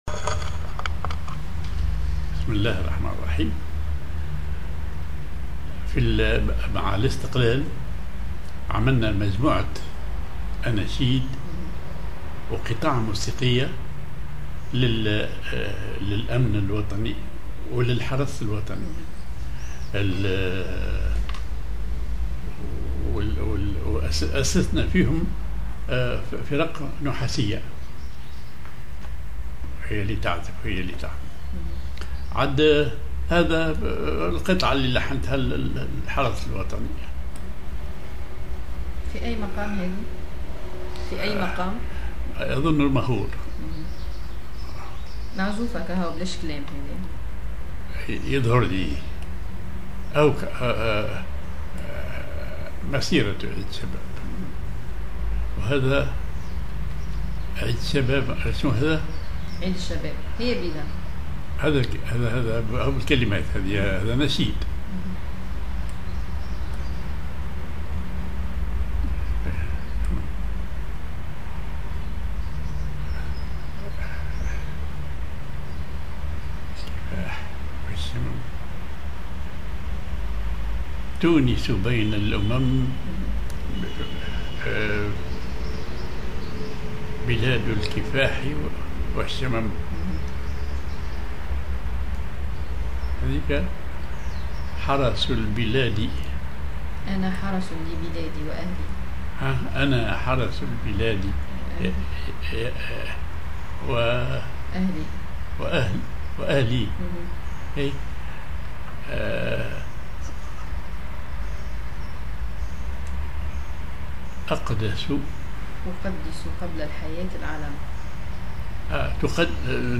Maqam ar ماهور
genre أغنية